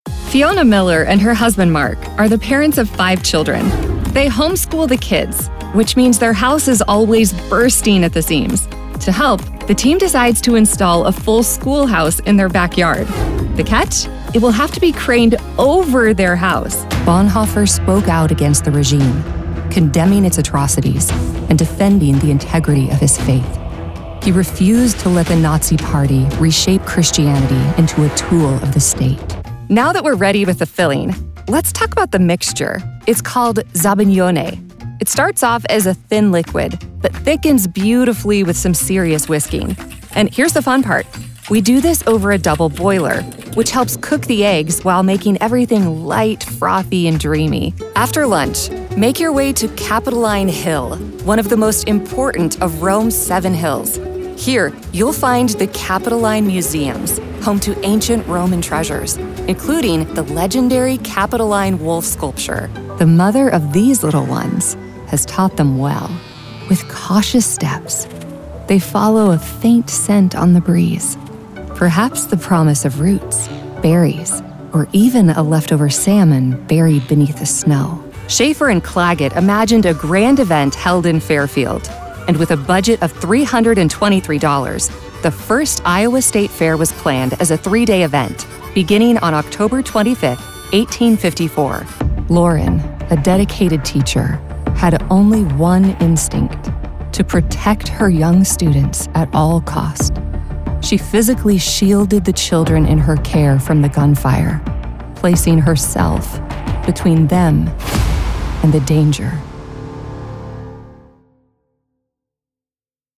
Narration